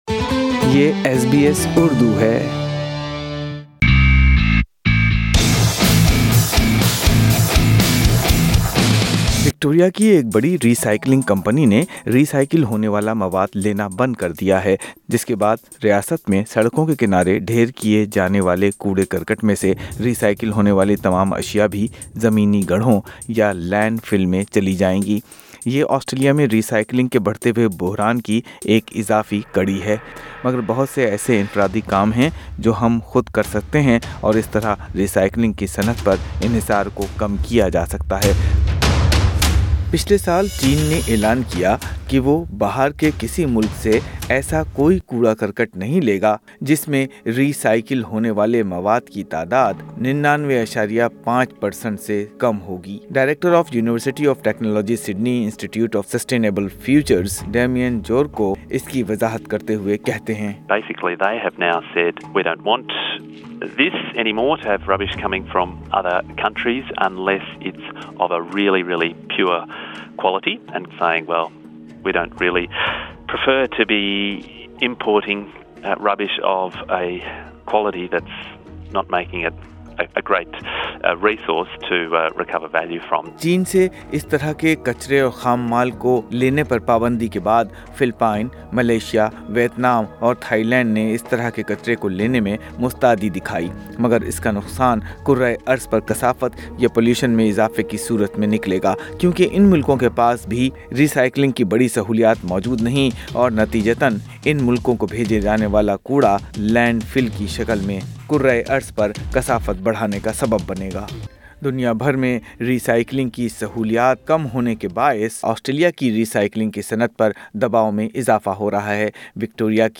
کیا آسٹریلینز ری سایکلنگ کے ممکنہ بحران سے نمٹنے کے لئے خود کیا کر سکتے ہیں ؟ سنئے ماہرین کی رائے پر مبنی پوڈکاسٹ۔